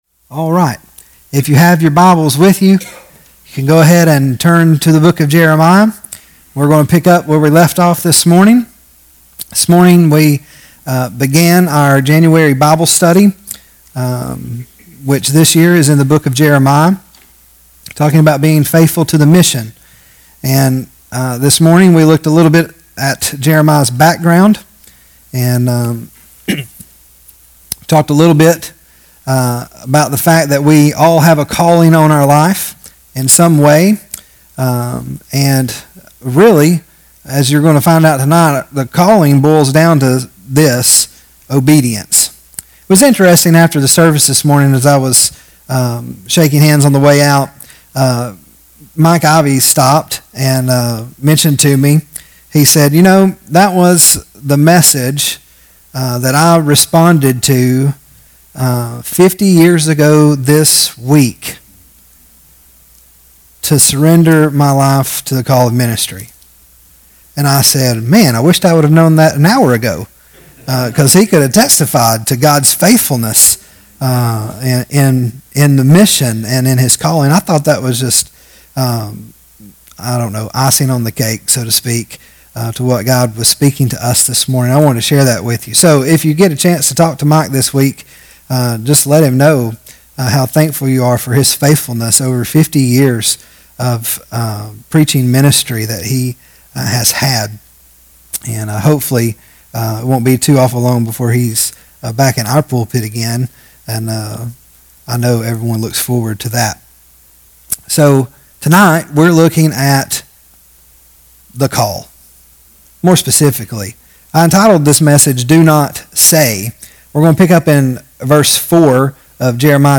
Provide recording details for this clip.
Service Type: PM Service